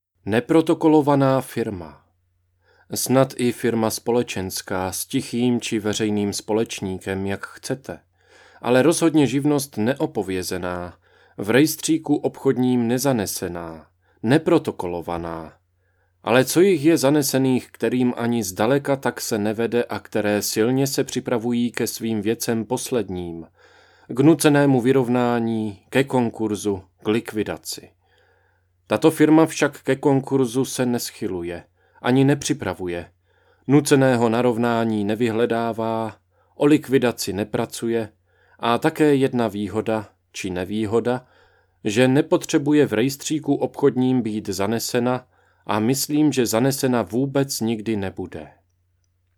Mluvené slovo z psaného textu
Základní úprava - odstranění přeřeků, odstranění šumu je samozřejmostí.